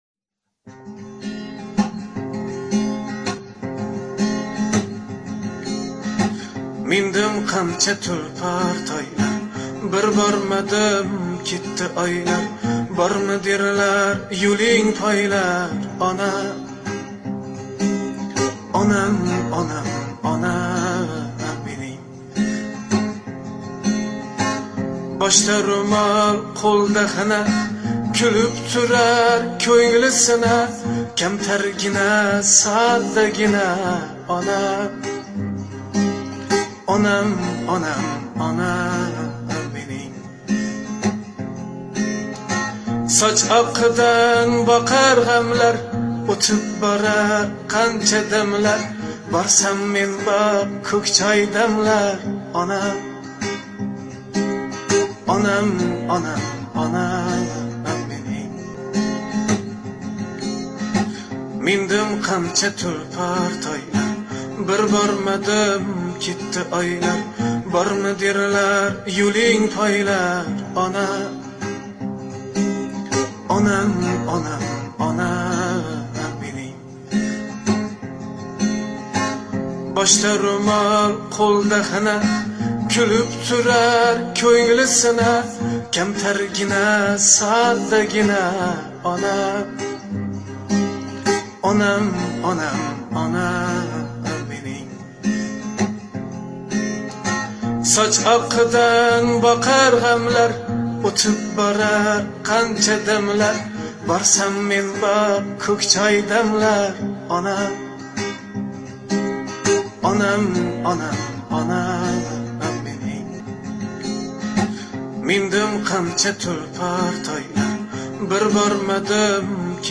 jonli ijro